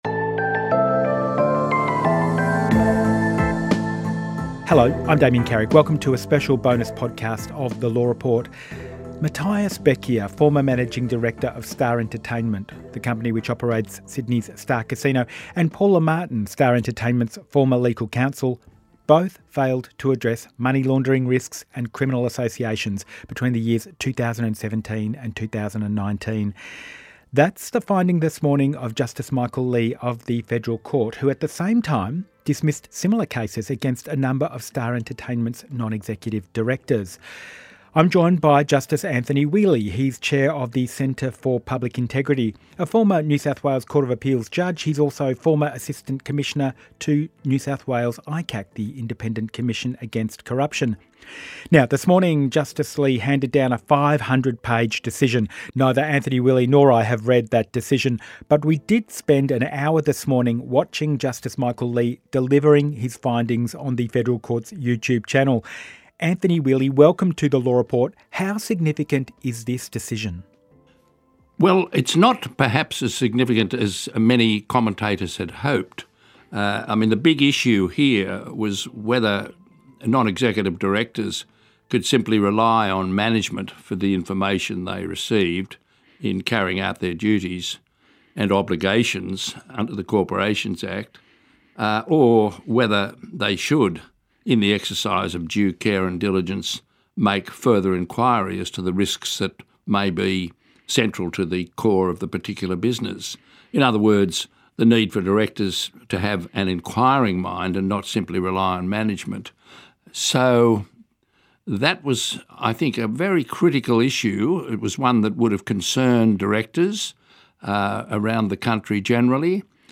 Guest: Anthony Whealy, former NSW Supreme Court judge, Chair of the Centre for Public Integrity and a Former New South Wales Court of Appeals Judge, he’s also a former assistant commissioner to the NSW ICAC.